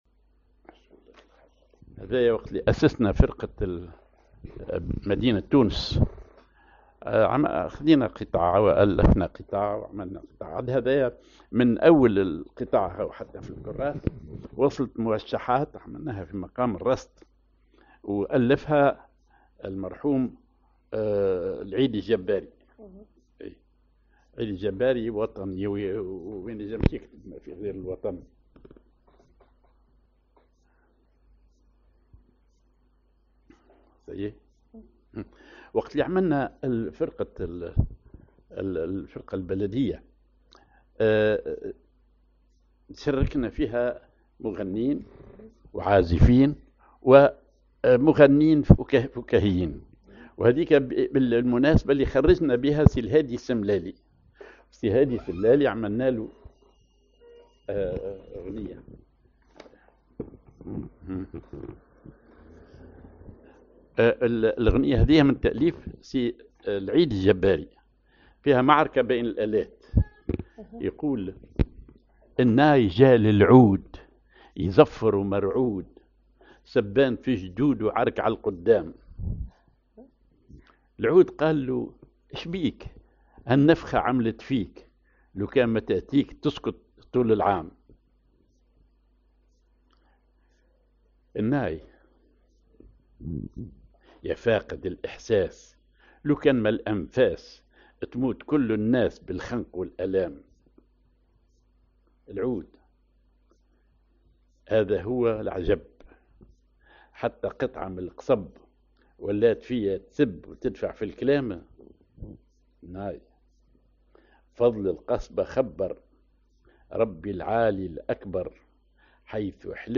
Maqam ar هزام على درجة السيكاه
genre أغنية